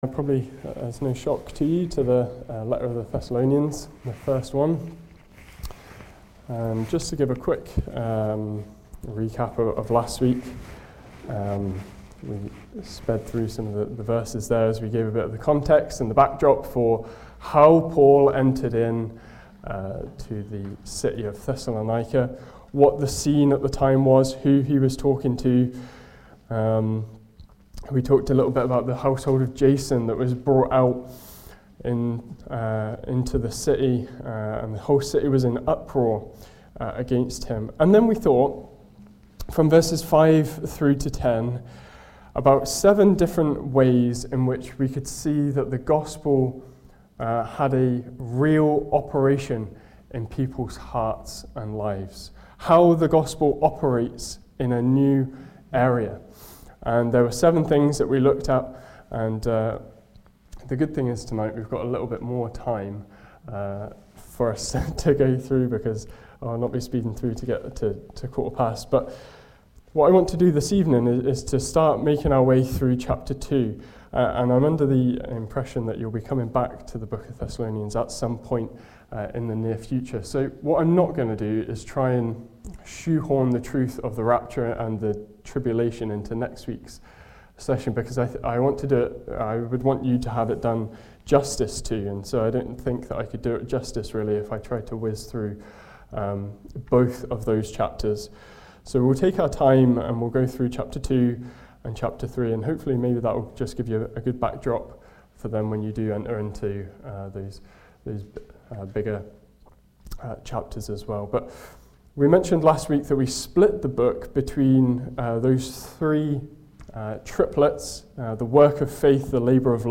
Passage: 1 Thessalonians 2:1-20 Service Type: Ministry